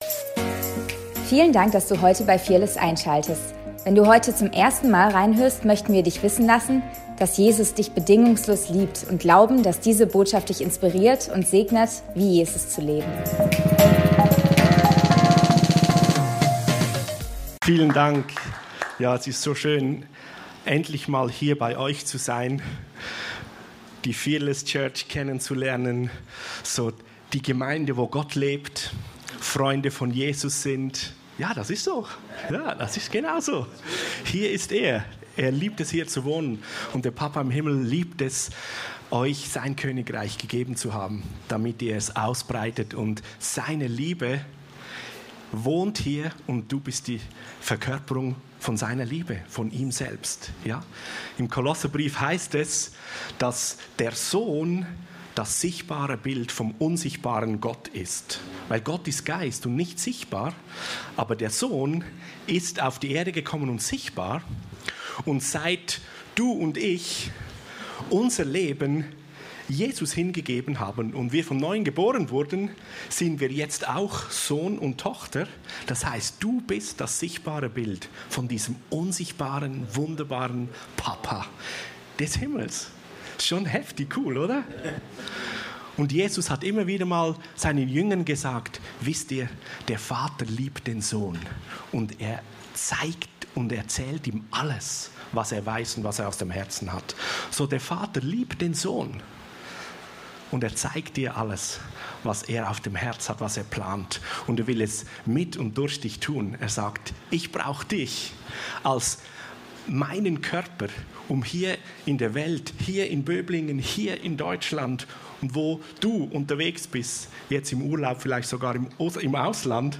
Predigt vom 20.07.2025